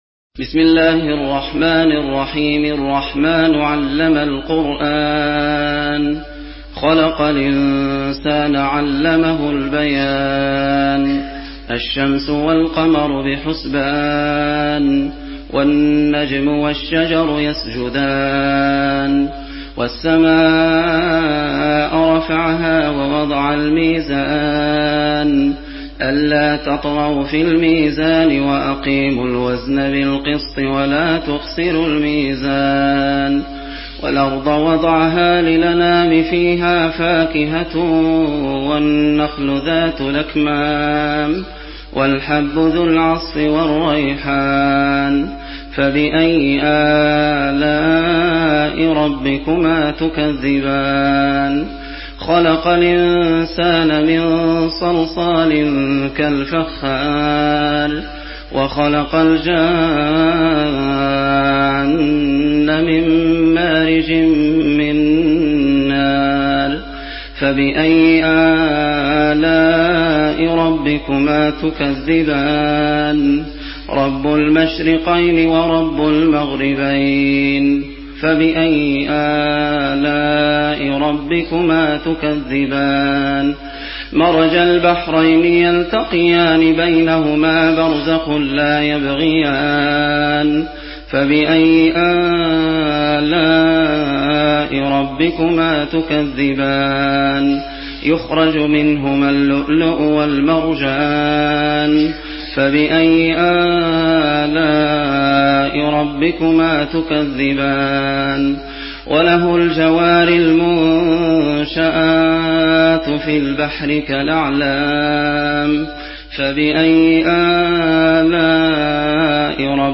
برواية ورش عن نافع